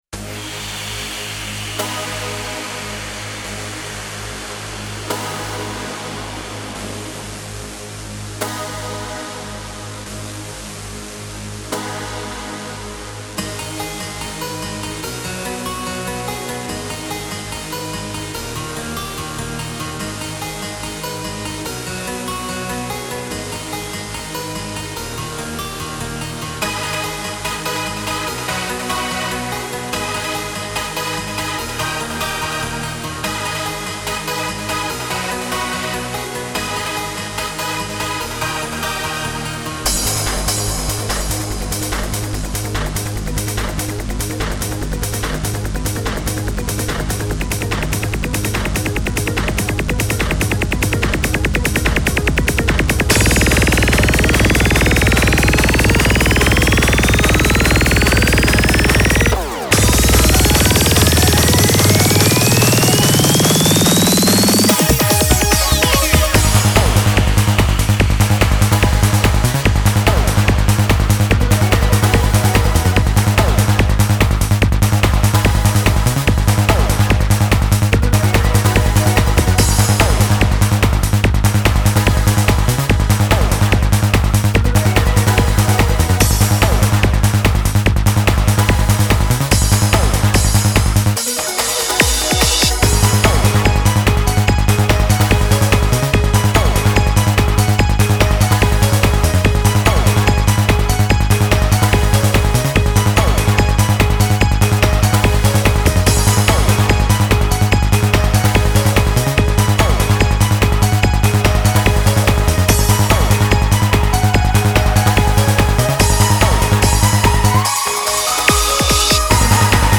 Dreamer【Full-on trance